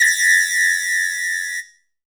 WHISTLE2LO.wav